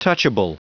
Prononciation du mot touchable en anglais (fichier audio)
Prononciation du mot : touchable